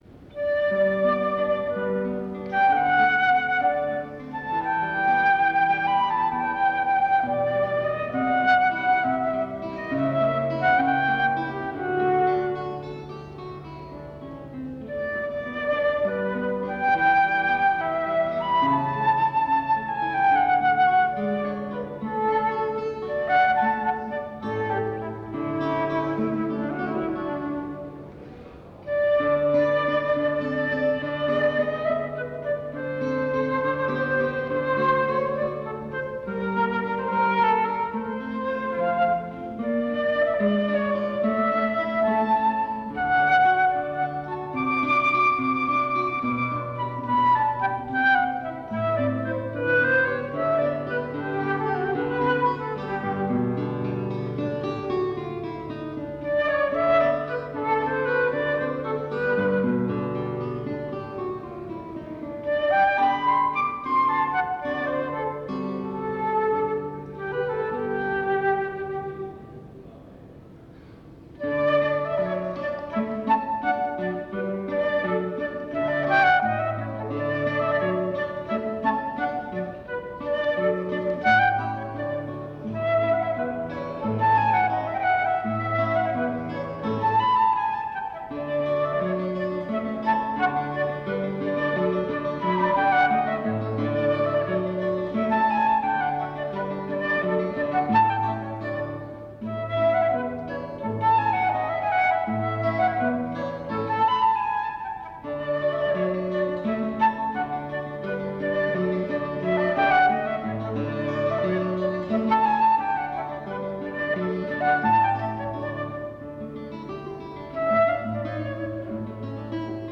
Duo flauto e chitarra
flauto
chitarra
Circolo Eridano, Torino 2 Aprile 1993